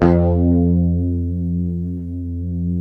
F#2 HSTRT MF.wav